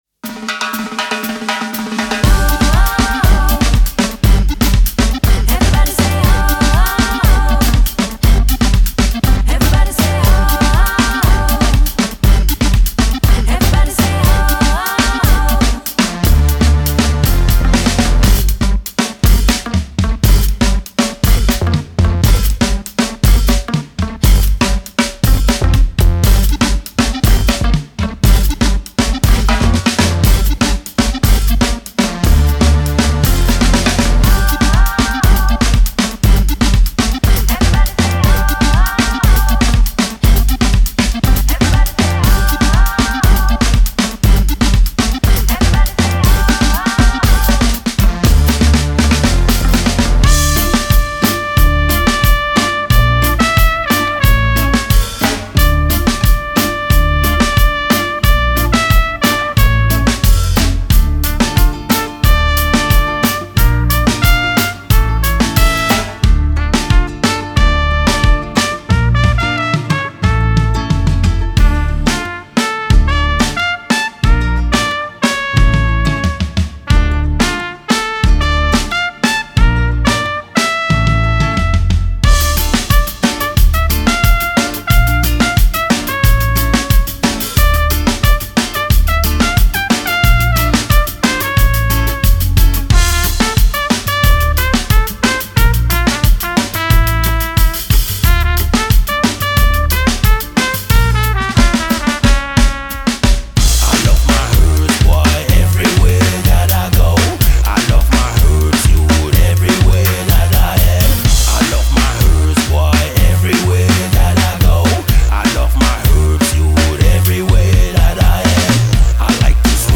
Genre:Reggaeton
マルチトラックドラム録音
10,000以上のループが収録されており、ジャンル特有のクセになるグルーヴを忠実に再現しています。
キック＆スネアはタイトでオフビートアクセントが効いており、グルーヴを持続させます。
ハイハット＆パーカッションはシンコペーションが効いて遊び心があり、ダンスフロアで感じる弾みを再現します。
ボンゴ、ハンドバレ、カウベル、カバサなどのパーカッションレイヤーがさらなる動きとラテン風味を加えます。
フィルはシンプルながら音楽的で、グルーヴを損なわずにエネルギーを維持します。
これらのドラムはすべてプロによるライブ演奏で収録されており、リアルでグルーヴ感のあるサウンドです。
EQと軽いコンプレッションが施されており、ミックスにそのまま使用できる一方で、自由なプロセッシングも可能です。
重要：ドラム以外の楽器はすべてデモ用です。
10225 Acoustic Drum Loops
Live Performed & Played